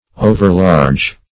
Overlarge \O"ver*large"\, a.